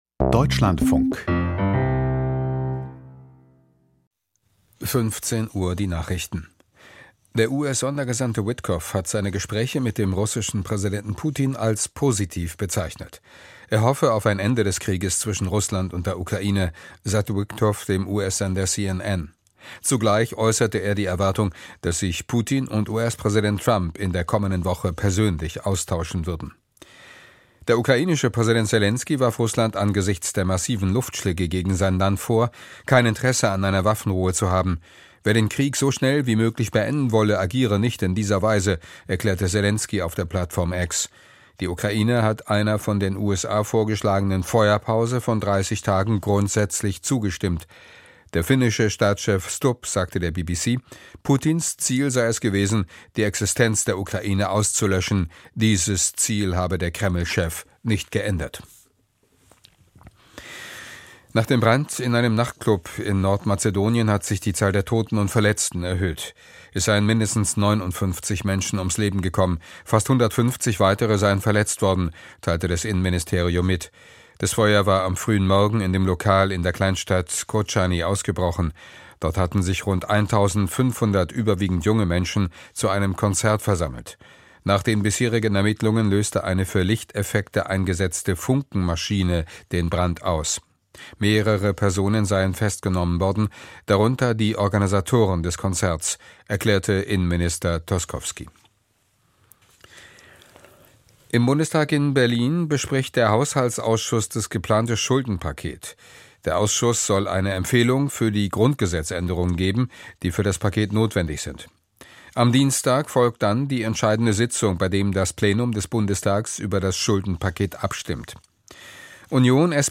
Die Deutschlandfunk-Nachrichten vom 16.03.2025, 15:00 Uhr